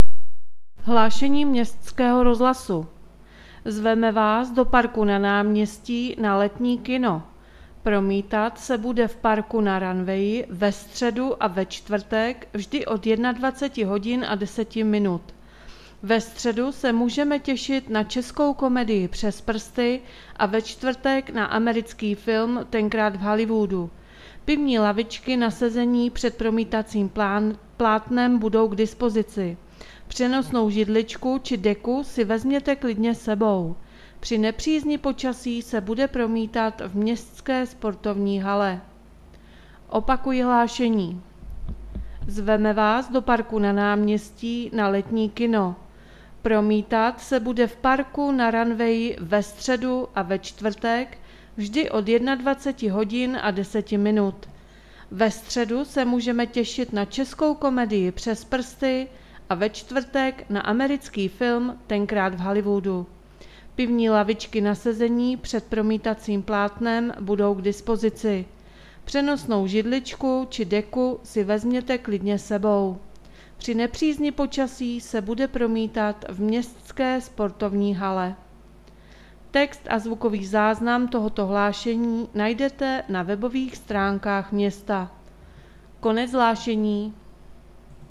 Hlášení městského rozhlasu 10.8.2020